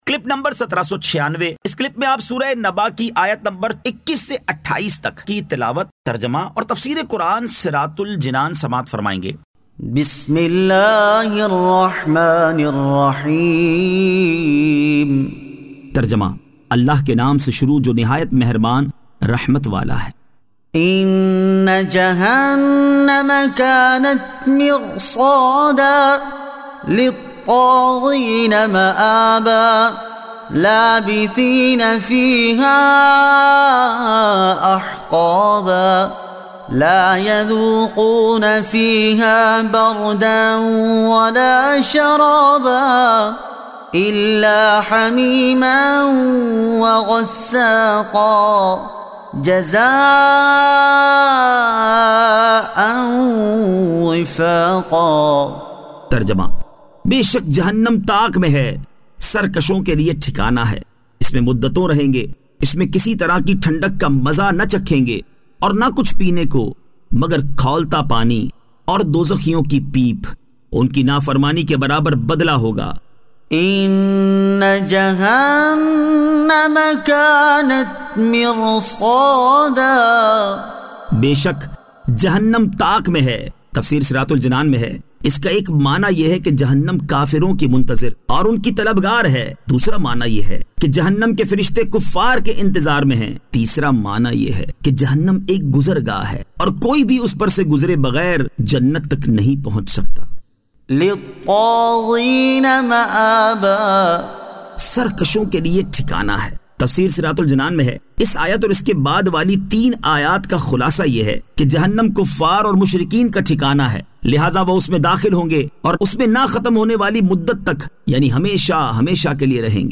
Surah An-Naba 21 To 28 Tilawat , Tarjama , Tafseer